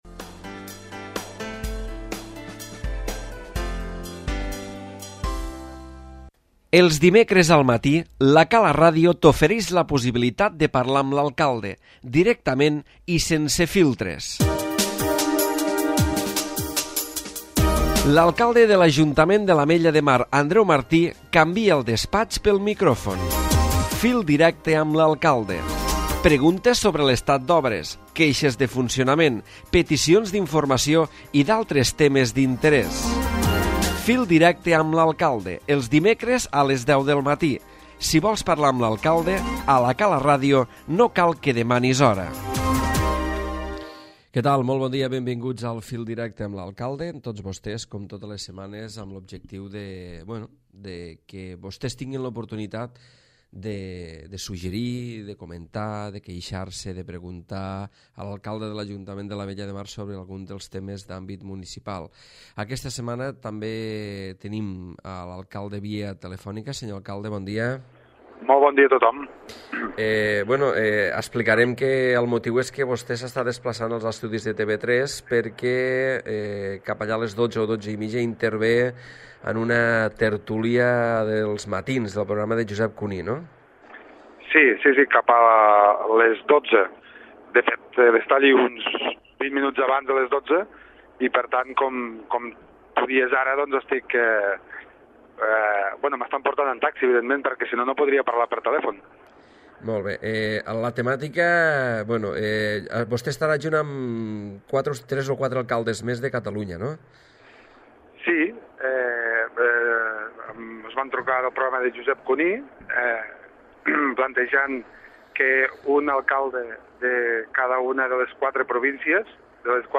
L'alcalde Andreu Martí ha parlat al fil directe d'avui de les gestions realitzades per buscar el suport institucional cap a la defensa de l'activitat del sector tonyinaire de l'encerclament, tant a nivell del congrés com del Parlament de Catalunya.